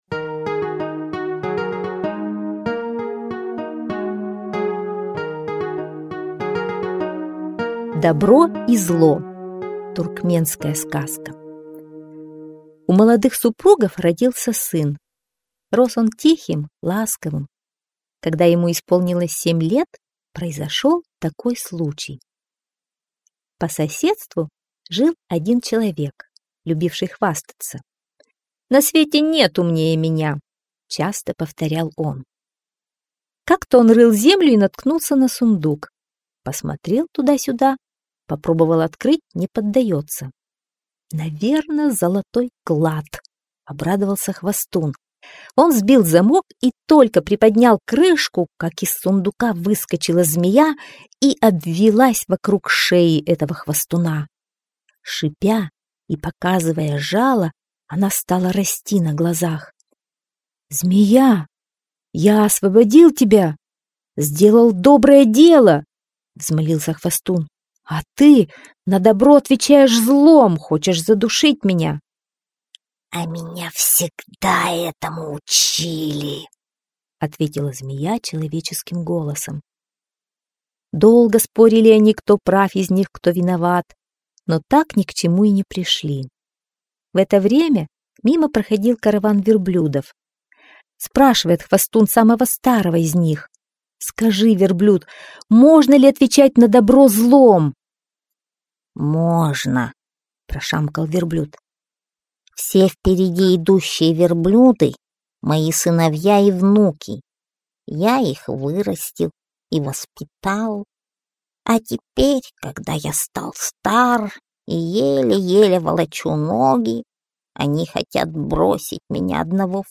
Добро и зло - туркменская аудиосказка - слушать онлайн